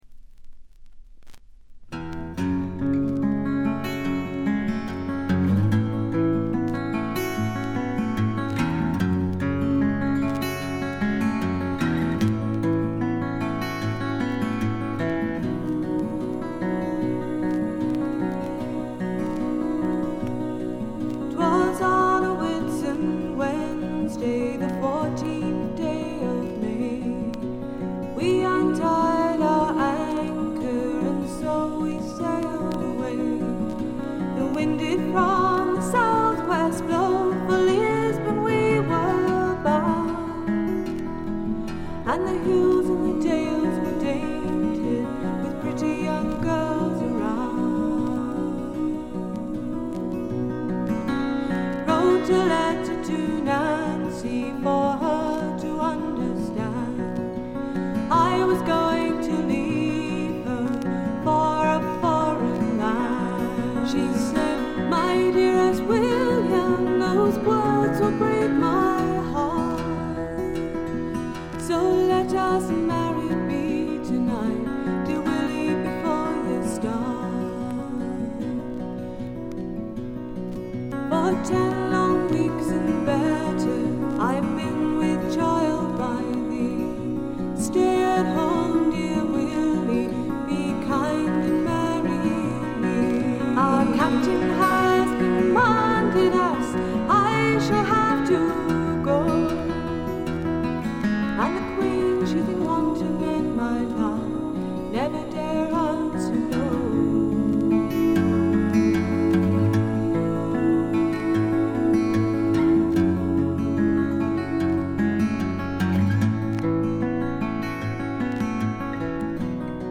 軽微なチリプチ少々（特にA1）。
試聴曲は現品からの取り込み音源です。